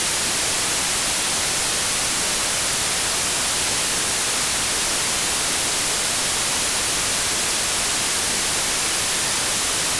front_noise_FuMa.wav